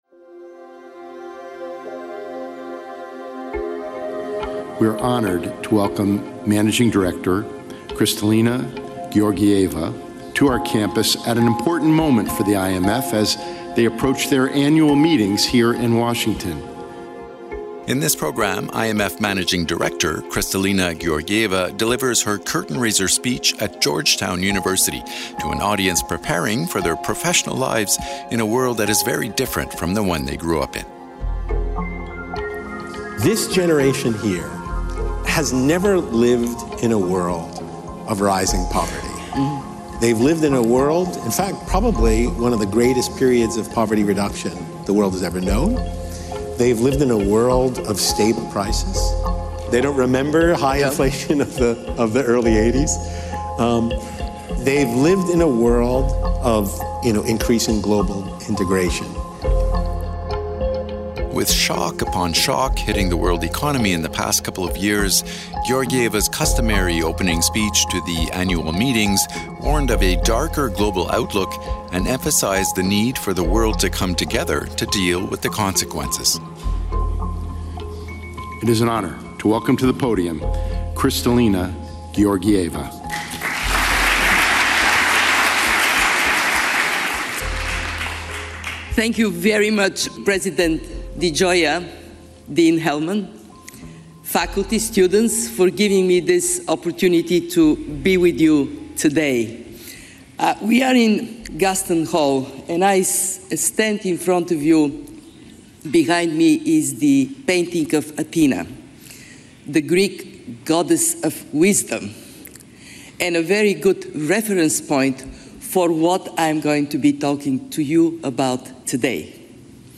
With shock upon shock hitting the world economy in the last three years, IMF Managing Director Kristalina Georgieva's customary opening speech to the Annual Meetings warned of a darker global outlook and emphasized the need for the world to come together to deal with the consequences.